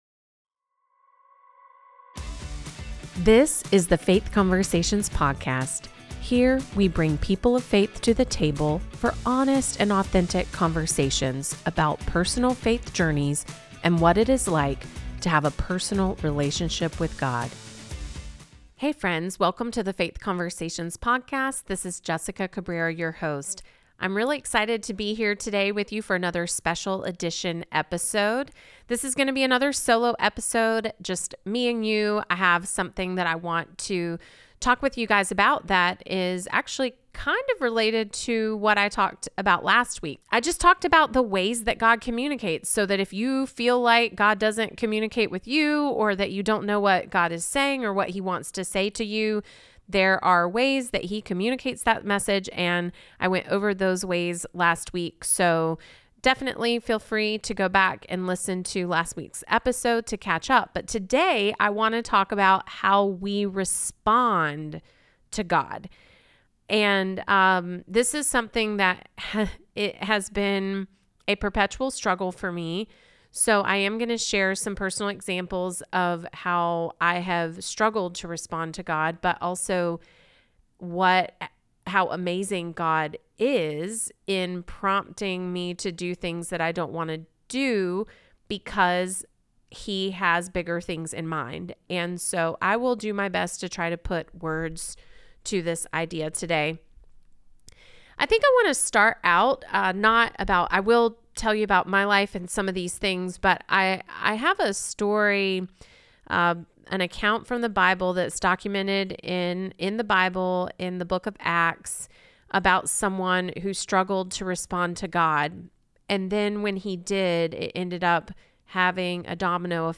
In this honest and deeply personal solo episode